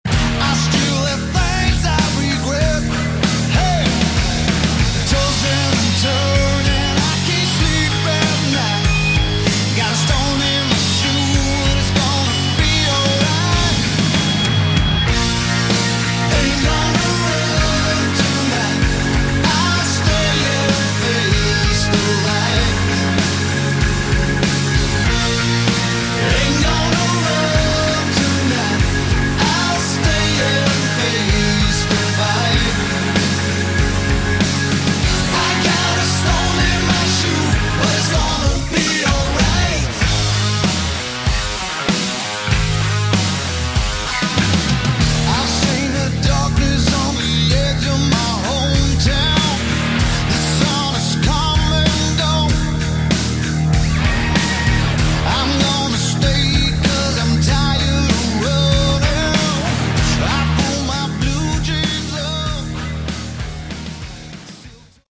Category: Hard Rock
Guitar Solo